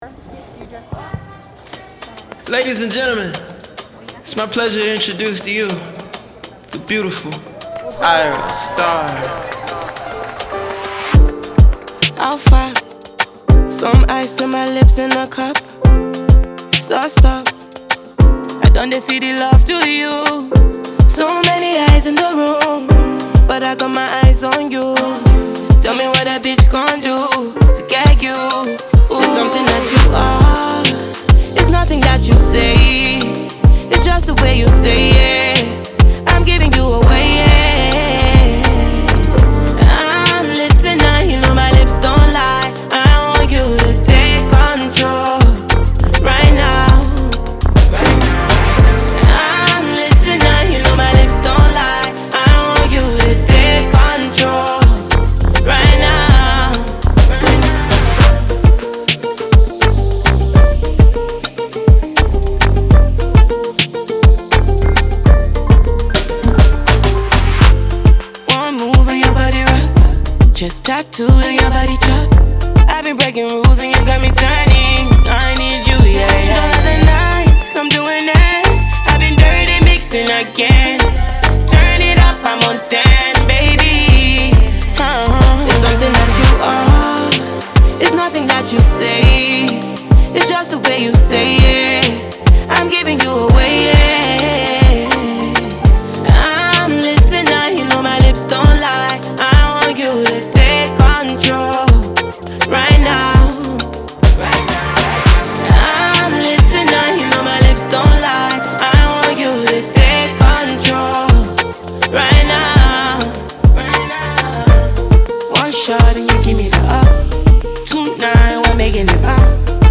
• Жанр: Pop